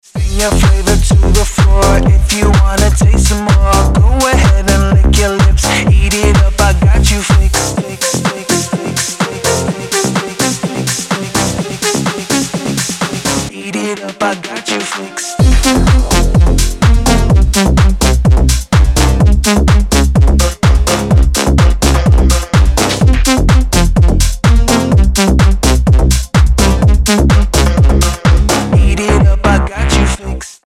• Качество: 320, Stereo
мужской голос
EDM
Tech House
Tech house, который качает прямо со старта